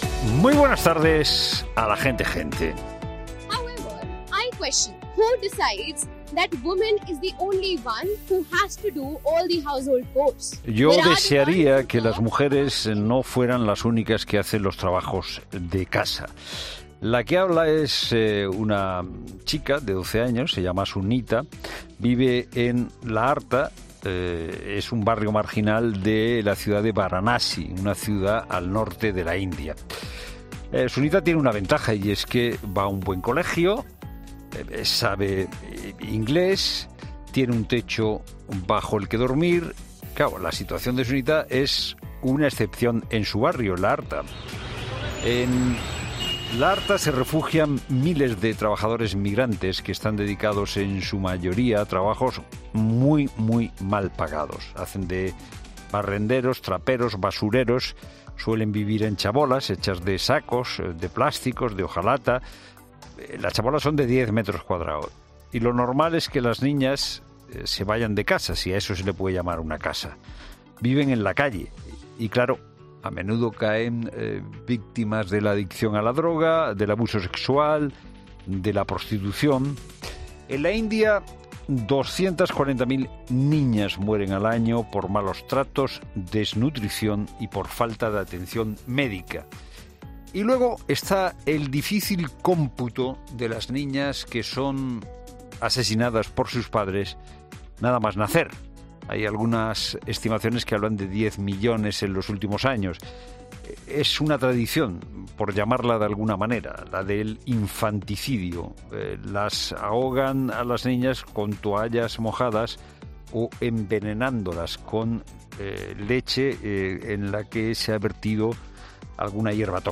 Monólogo de Fernando de Haro